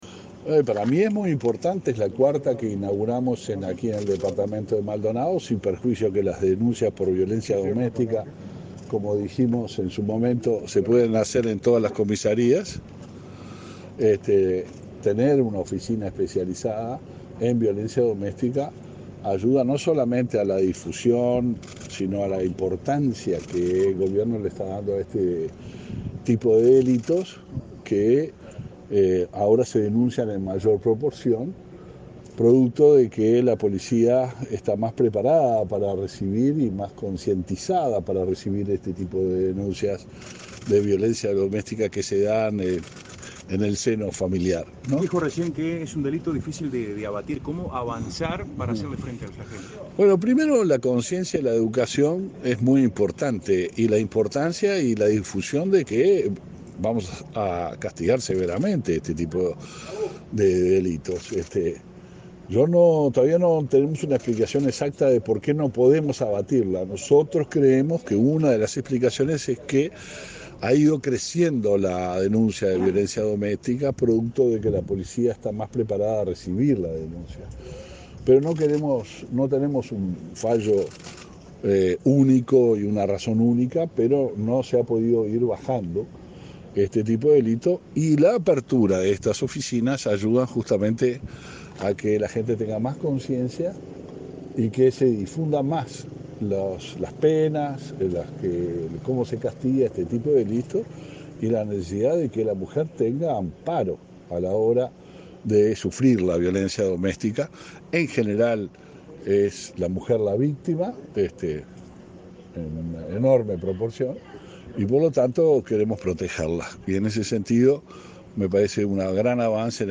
Declaraciones a la prensa del ministro del Interior
El ministro del Interior, Luis Alberto Heber, participó este viernes 4 en la inauguración de la Oficina de Violencia Doméstica y de Género de Aiguá,